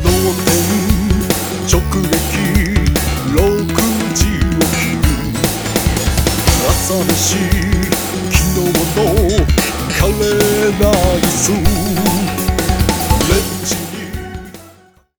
(C)複数のステレオバスを使ってn12でミックスダウンした場合
あきらかにドラムのヌケが違う(笑)他のパートも、(A)(B)と比較してはっきり聞こえてきます。